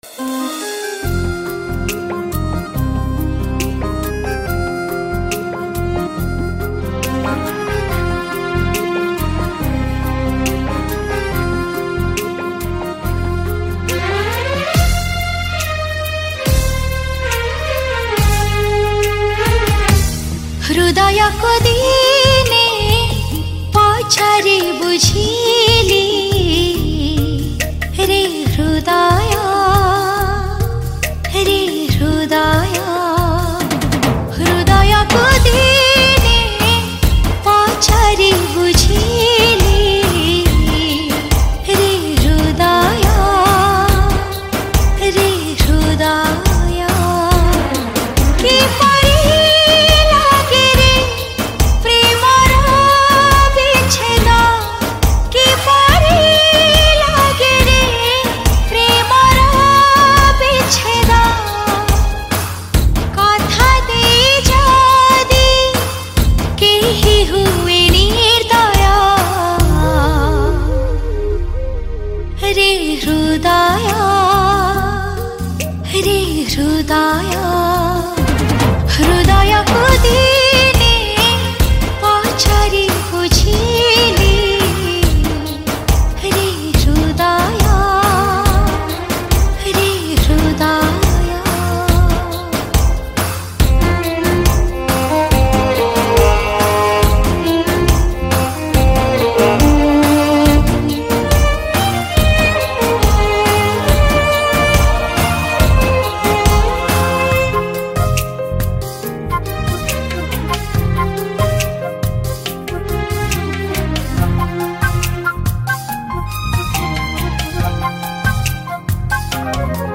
New Odia Sad Song